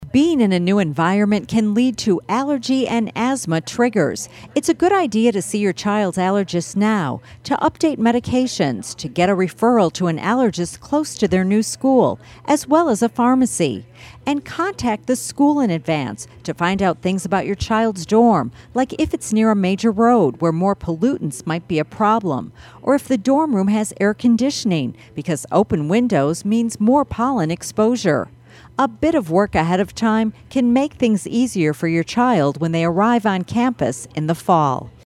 Health Reporter